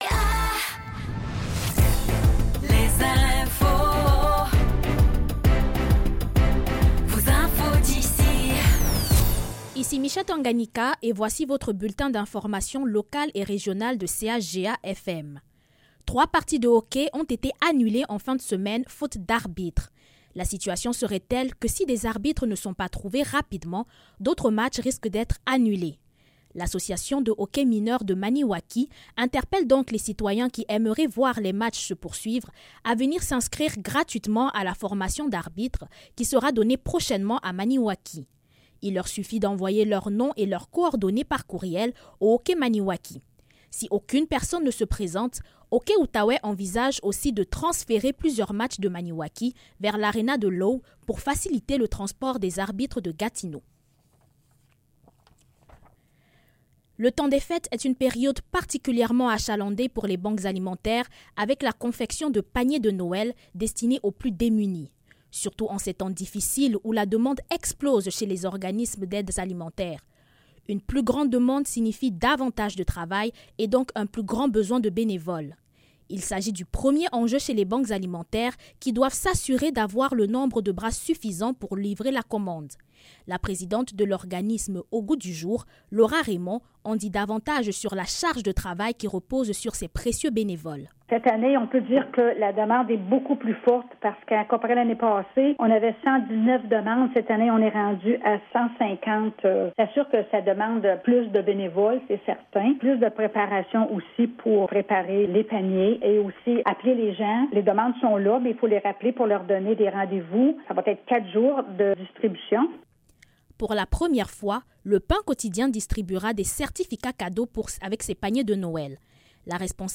Nouvelles locales - 10 décembre 2024 - 15 h